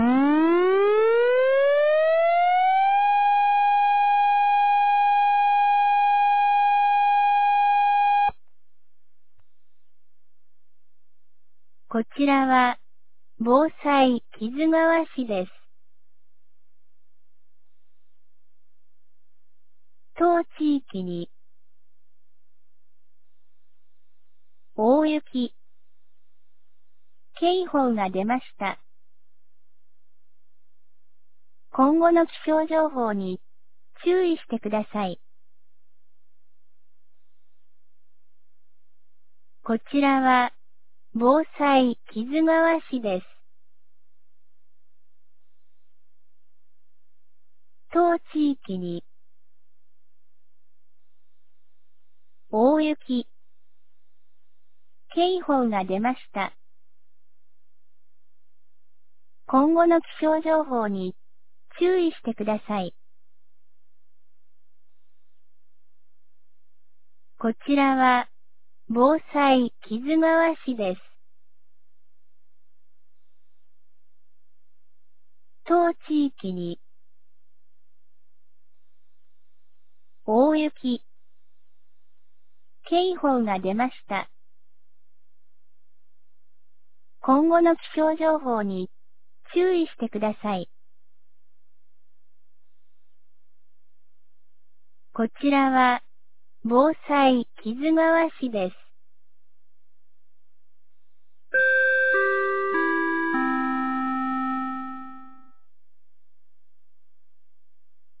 2025年11月12日 11時01分に、木津川市より市全域へ放送がありました。
放送音声